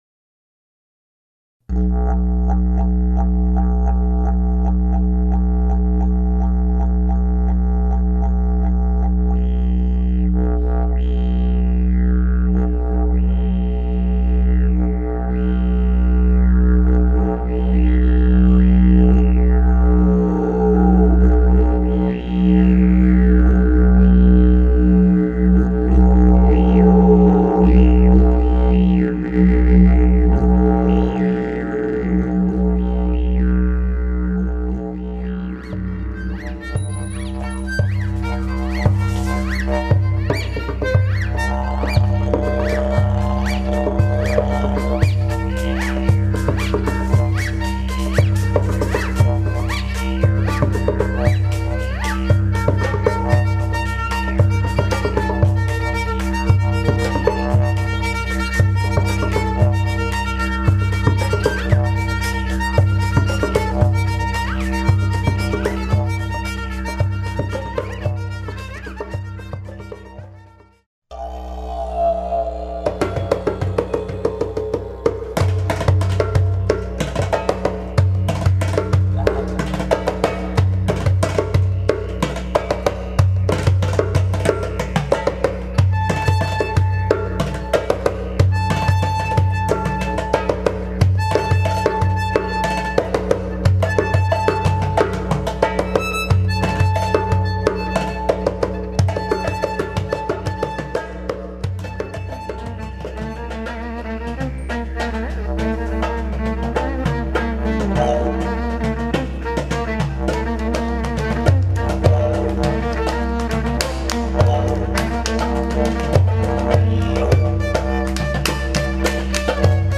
Członkowie zespołu wykorzystują unikalne instrumenty, tradycyjne dla społeczności Indii, Australii, Afryki i krajów arabskich.
Są to różnego rodzaju bębny, rury Didgeridoo, dzwonki indyjskie, kalimba, itp.